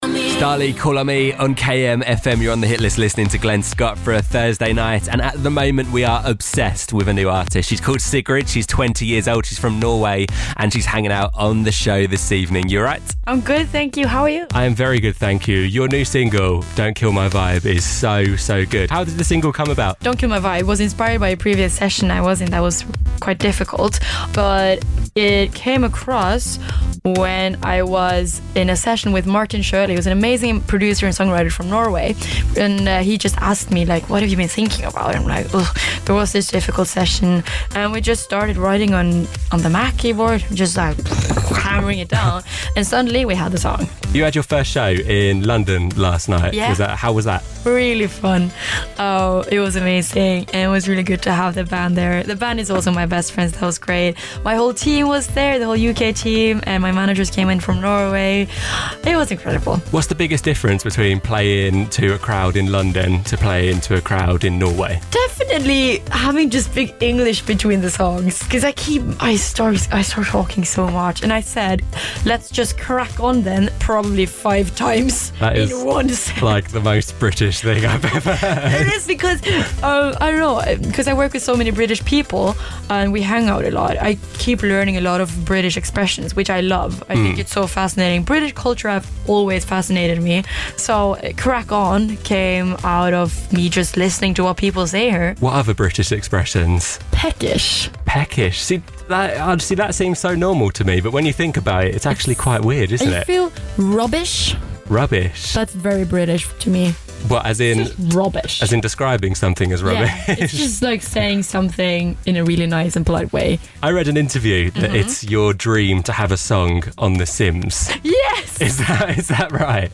Sigrid dropped by our studios to chat about her new single Don't Kill My Vibe and loads more on the Hit List!